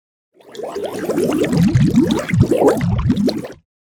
aggressive.mp3